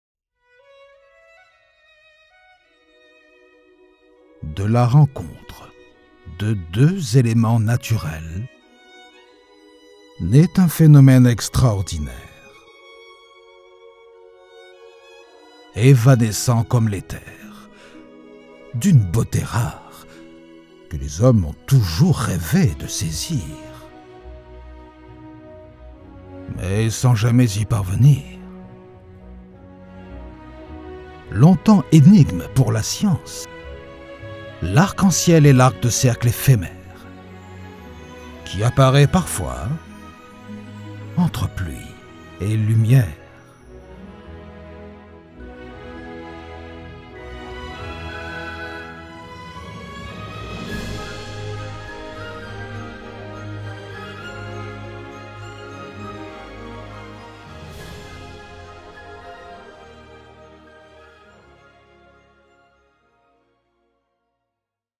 Une voix mature, posée, élégante
Sprechprobe: Sonstiges (Muttersprache):
A mature and elegant voice